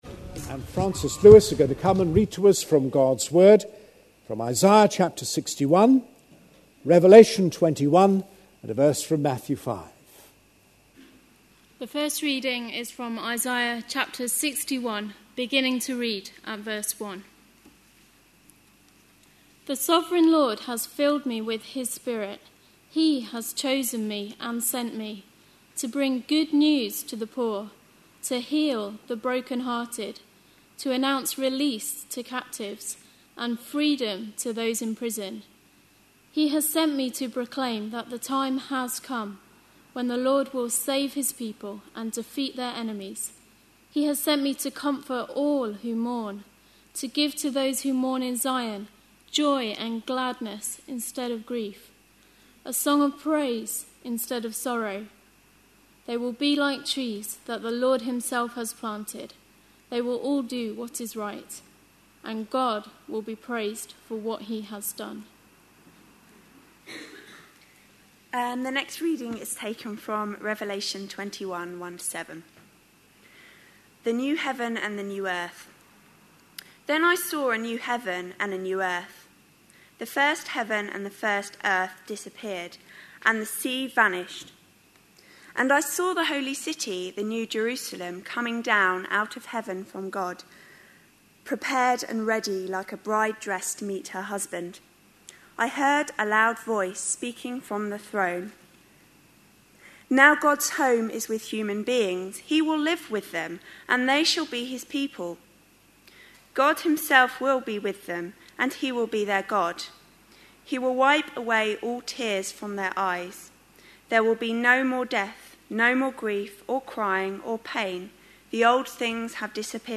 A sermon preached on 30th October, 2011, as part of our The Beatitudes. series.